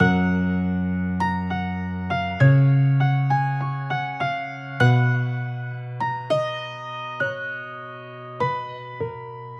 Tag: 100 bpm RnB Loops Piano Loops 3.23 MB wav Key : A